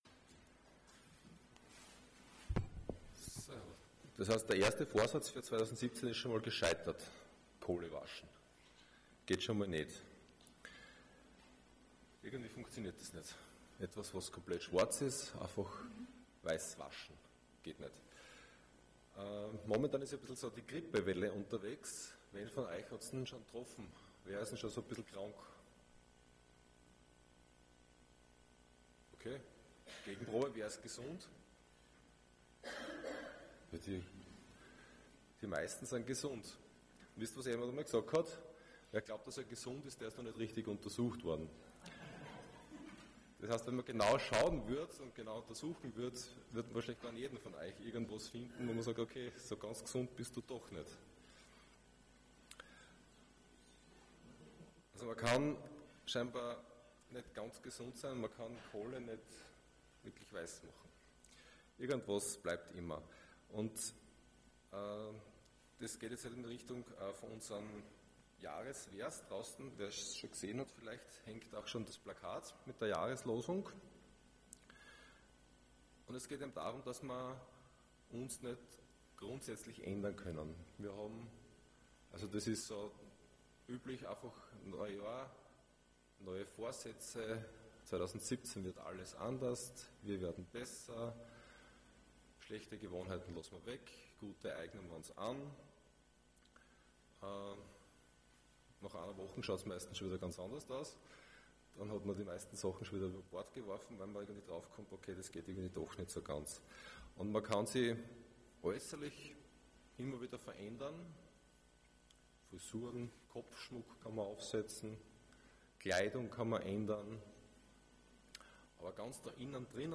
Passage: Ezekiel 36:26 Dienstart: Sonntag Morgen